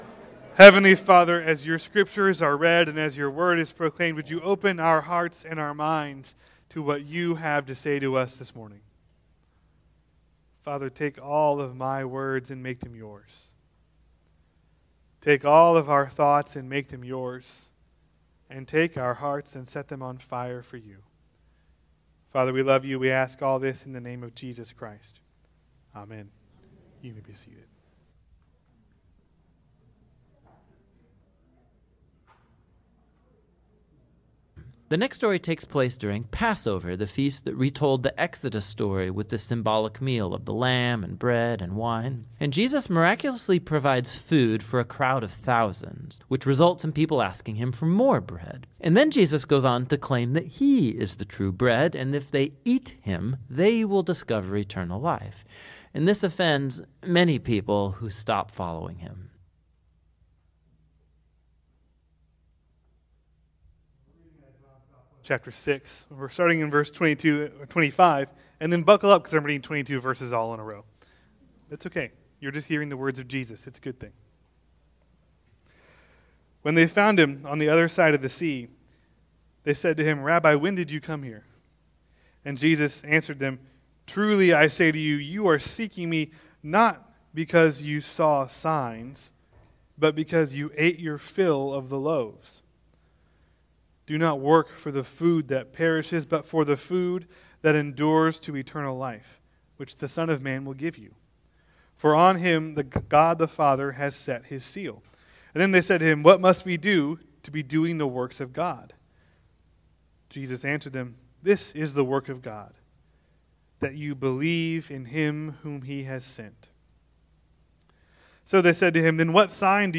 Sermons | Asbury Methodist Church